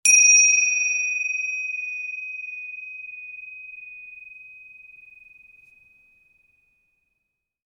Single chime
bell chime ding dry long metallic-overtones mid-sides MS sound effect free sound royalty free Voices